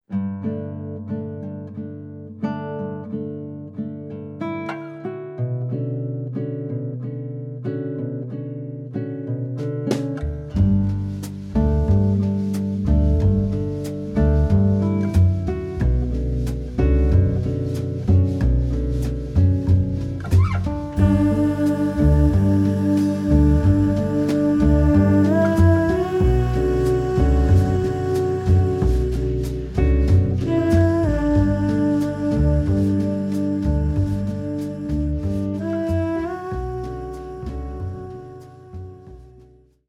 vocals
tenor sax
guitar
double bass
Drums